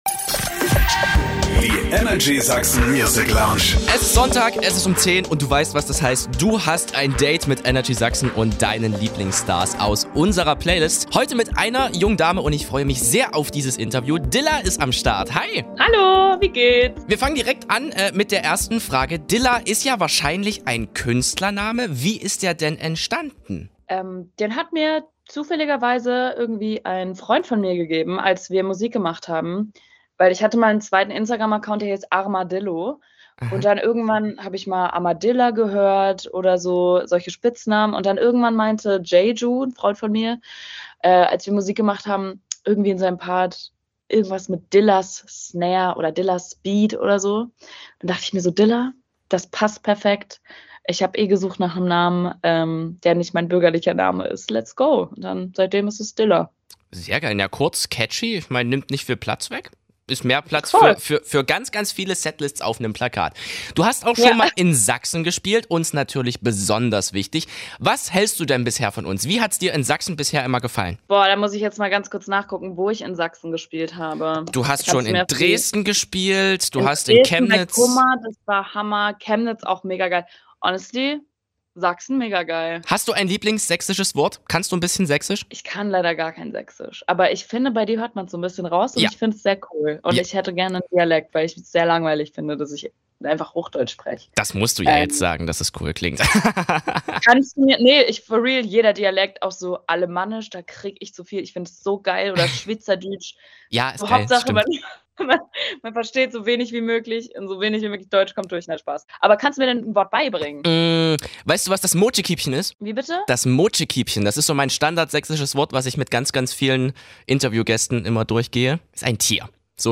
Freu dich auf ein super cooles Interview mit der Berliner Performerin!